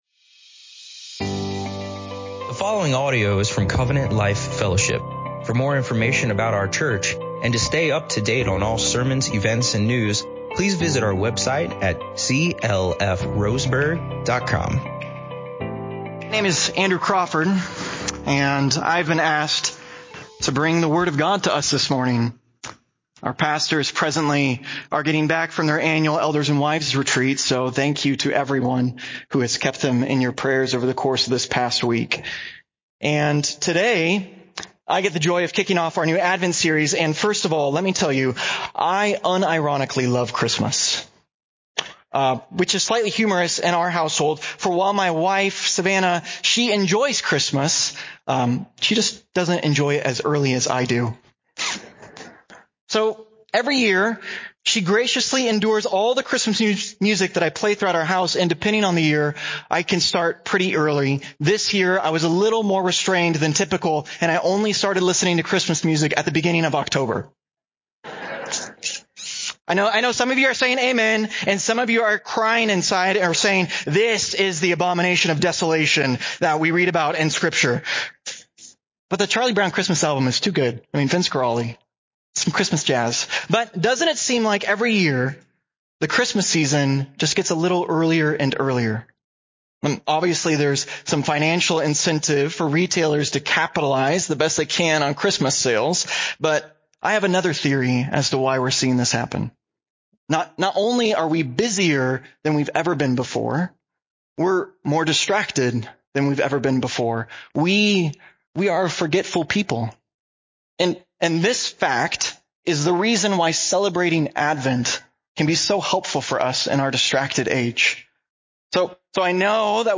This sermon will guide us through: Our Text: Genesis 3:15 “Are You the One?” – Exploring the longing for the Savior The One – Understanding the fulfillment of the promise Big Idea: We used to walk with God.